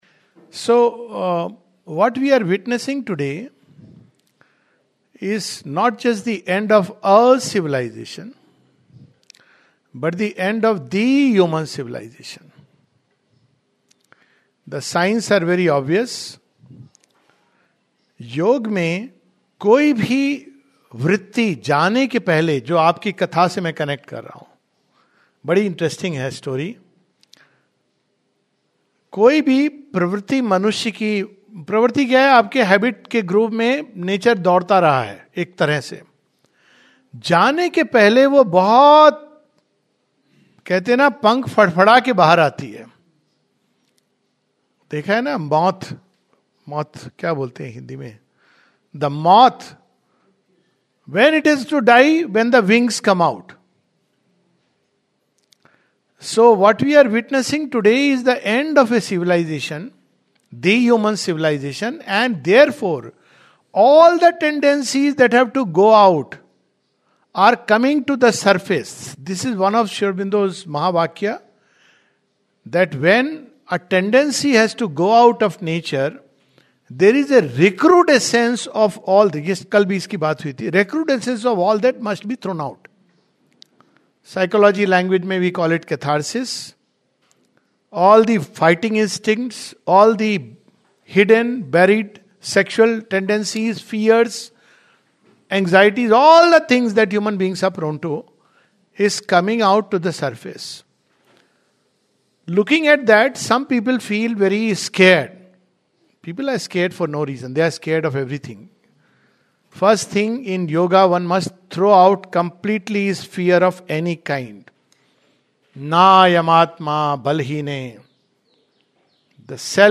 This is a concluding talk in series on Sri Aurobindo's vision of the future of earth and humanity, with the help of epic poem Savitri which contains Sri Aurobindo's ultimate revelations.